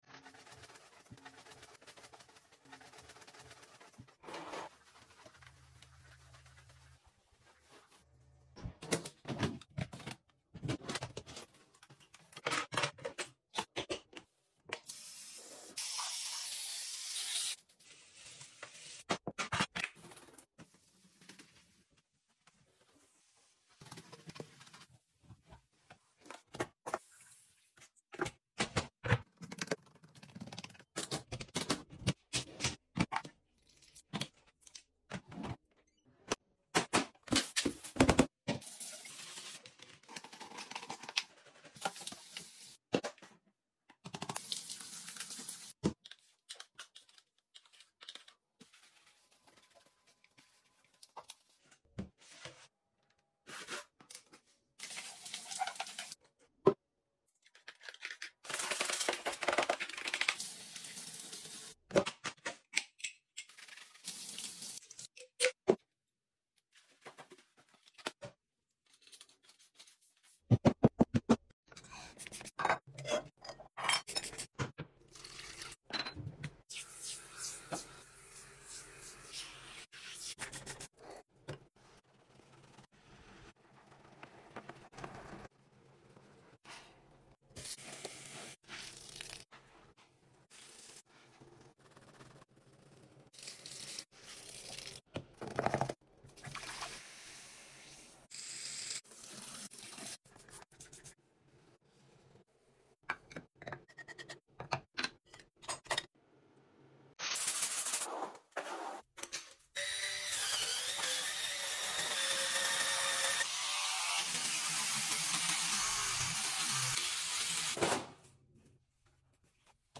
Asmr house deep cleaning 🧼🫧🧹🧼 sound effects free download